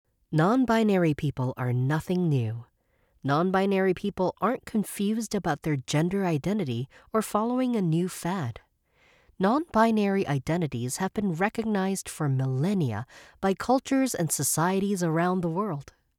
Teenager, Young Adult, Adult
Has Own Studio
singapore | natural
standard us | natural